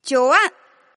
Index of /client/common_mahjong_tianjin/mahjonghntj/update/1308/res/sfx/tianjin/woman/